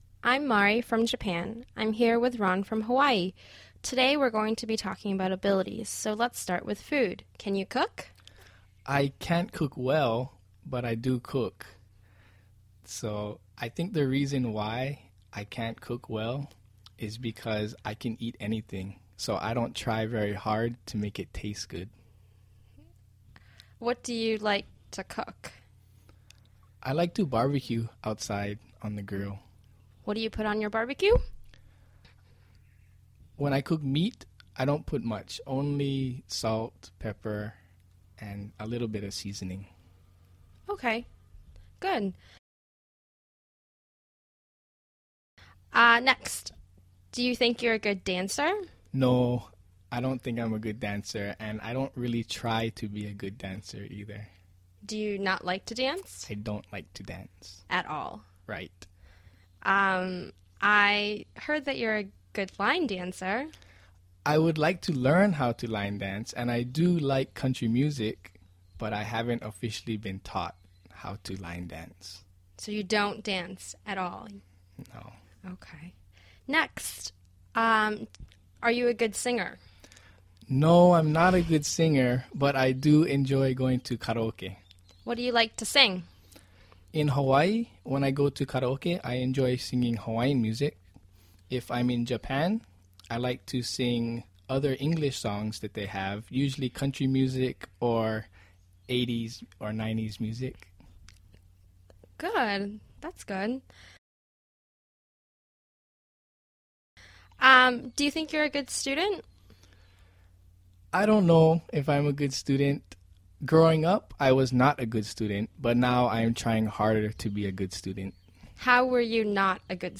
英语初级口语对话正常语速13：Ron的能力（mp3+lrc）